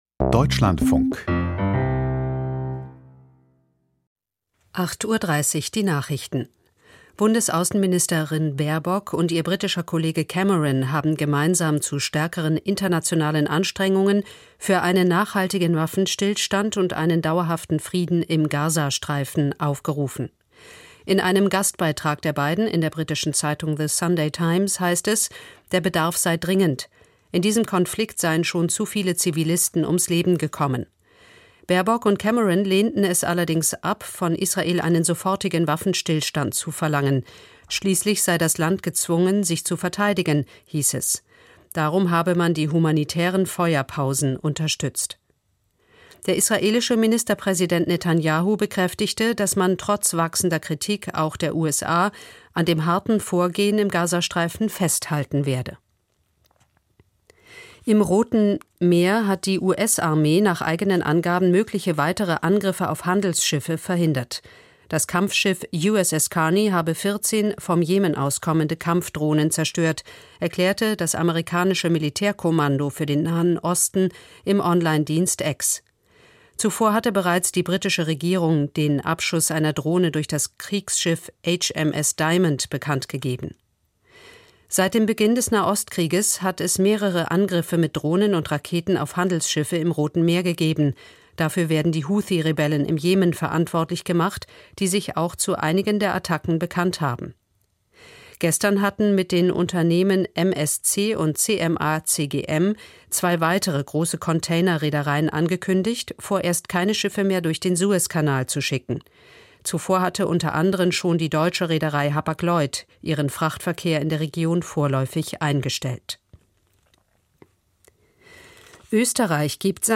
Nachrichten